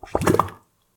bubble3.ogg